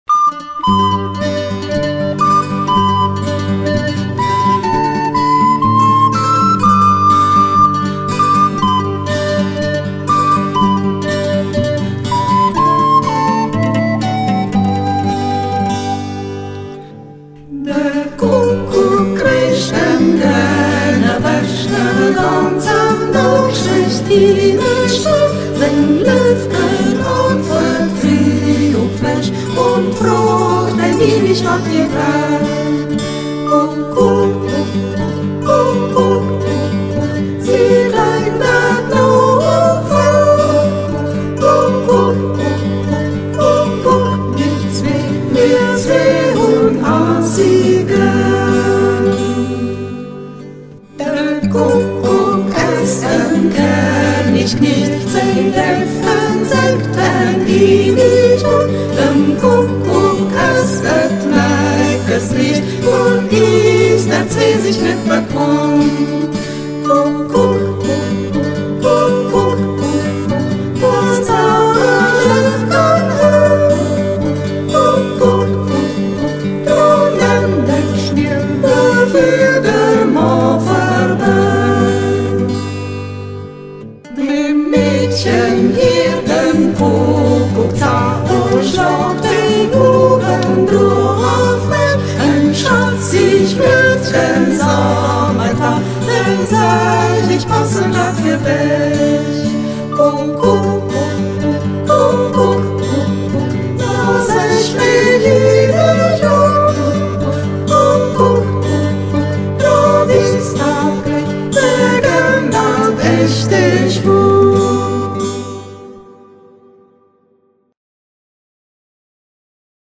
Umgangss�chsisch